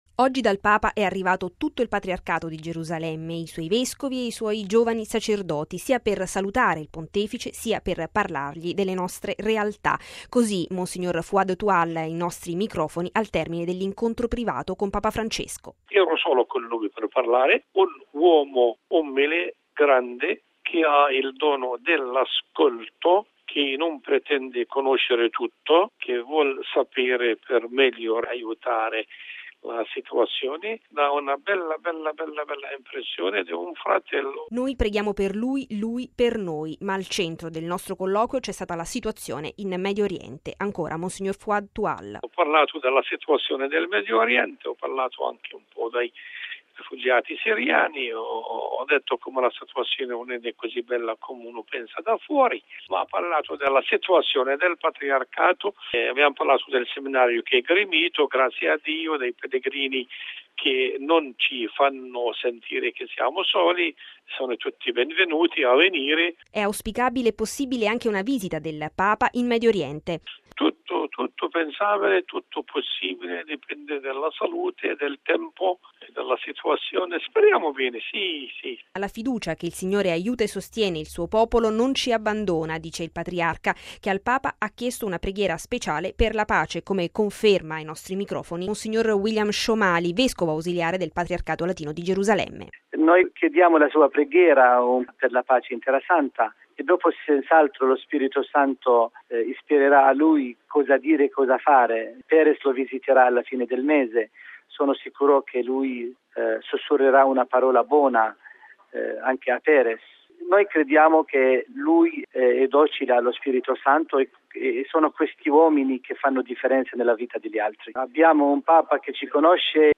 “Oggi dal Papa è arrivato tutto il Patriarcato di Gerusalemme, i suoi vescovi ed i suoi giovani sacerdoti, sia per salutare il Pontefice sia per parlargli delle nostre realtà”: così mons. Fouad Twal ai nostri microfoni, al termine dell’incontro privato con Papa Francesco: